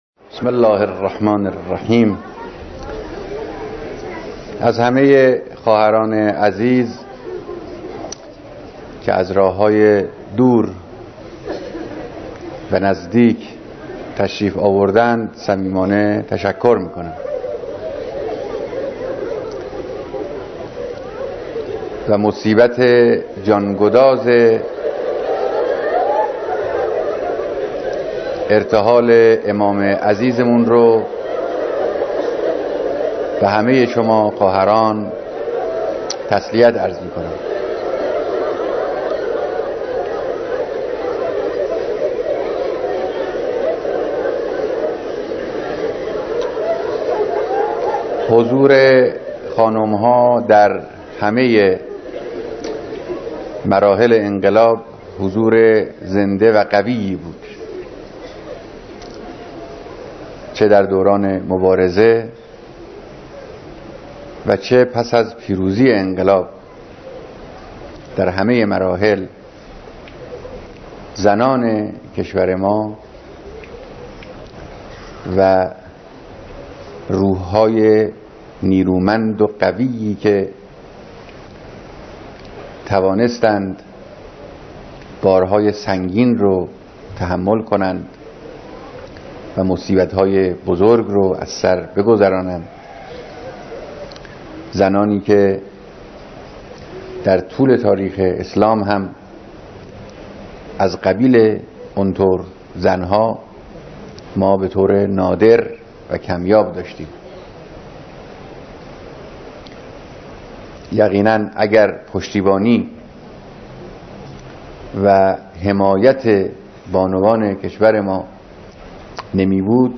بیانات رهبر انقلاب در مراسم بیعت جمعی از بانوان سراسر کشور